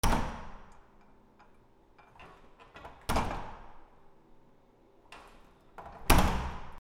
/ K｜フォーリー(開閉) / K05 ｜ドア(扉)
マンションの玄関のドア